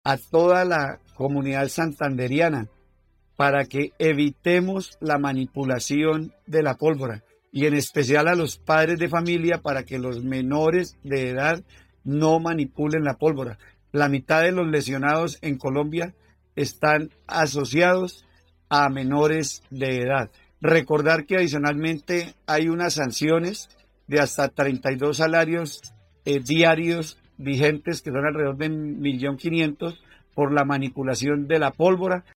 Secretario del Interior de Santander, Edwin Prada